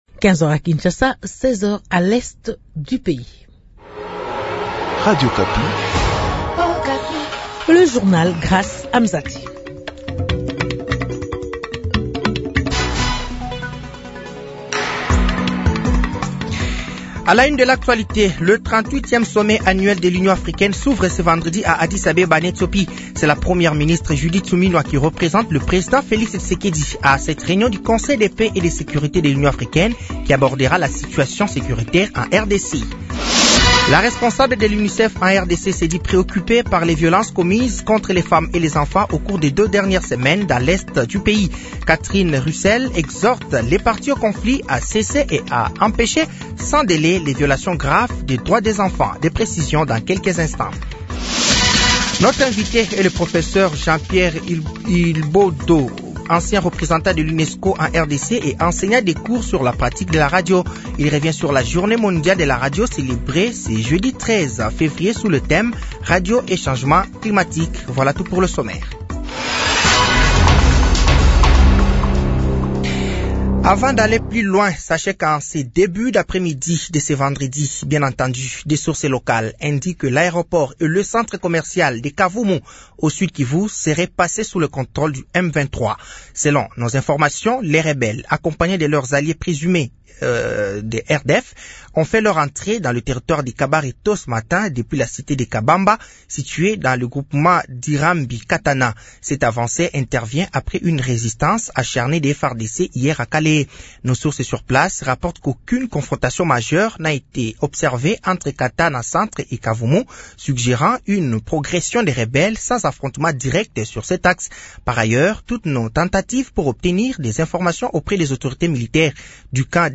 Journal français de 15h de ce vendredi 14 fevrier 2025